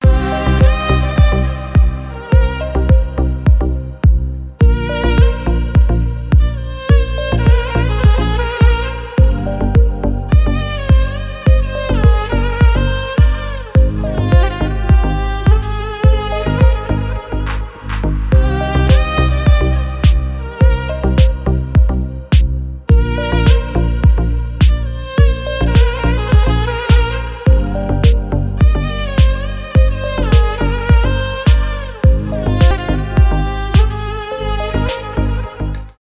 deep house , танцевальные
без слов , восточные
скрипка